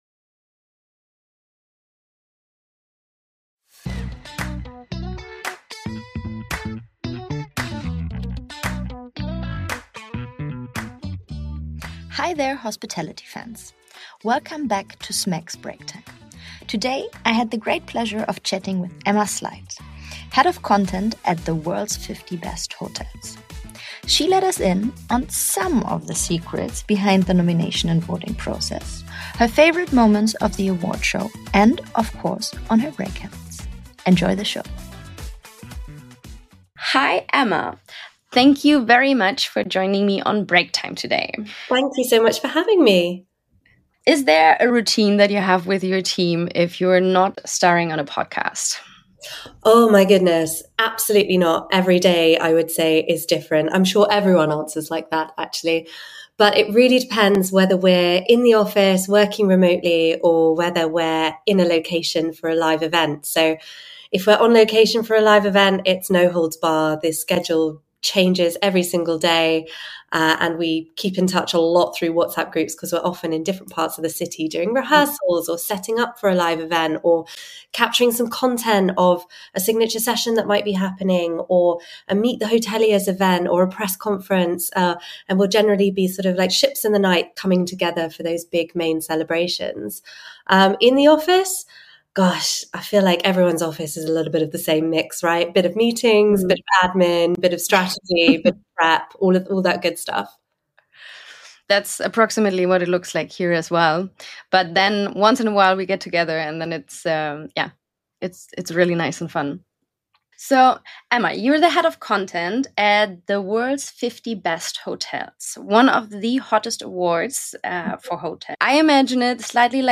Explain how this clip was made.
live from VRMA. They discuss the critical need for professionalization in the short-term rental industry amidst economic challenges and regulatory impacts.